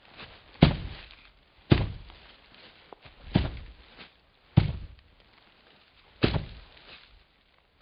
deep steps.wav